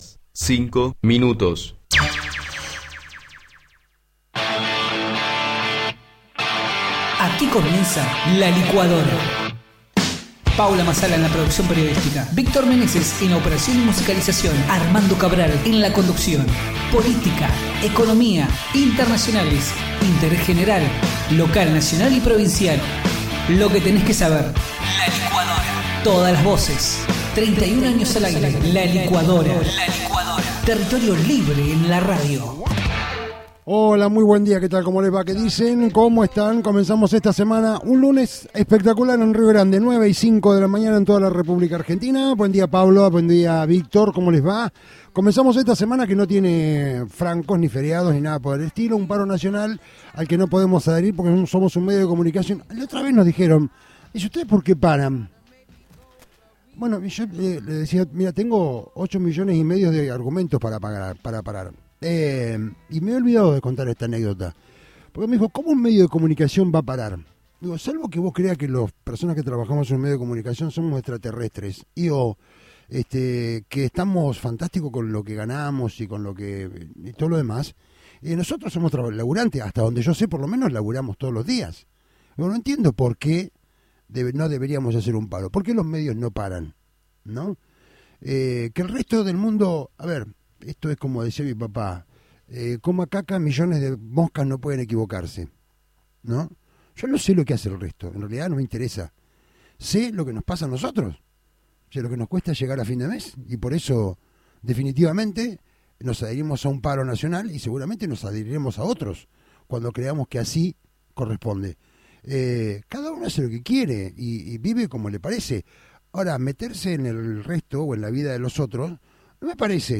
El secretario de seguridad de la provincia, Javier Eposto, dijo en dialogo con La Licuadora que quedamos todos consternados con el caso de estos dos chiquitos intoxicados con cocaína, nos sacó de eje, cuesta dar una noticia así